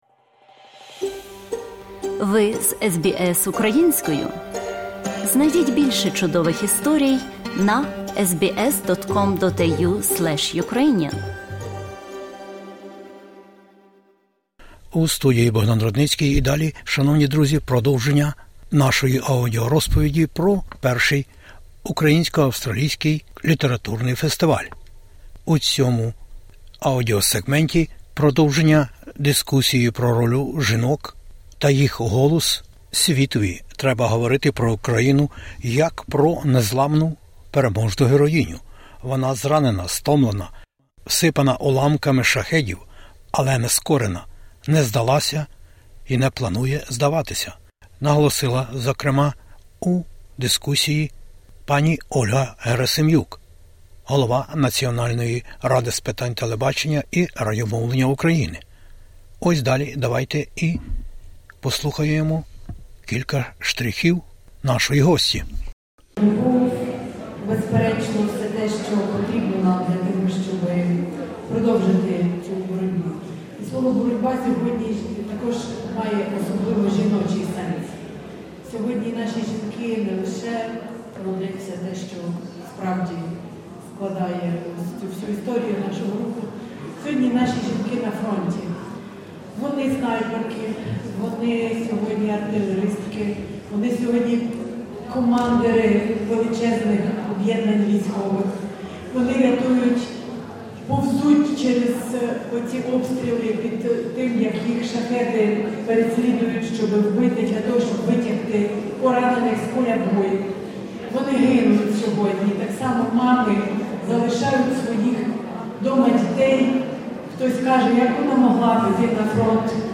Учасниці дискусії про роль українок у світі та жіночі голоси до світу - громадські активістки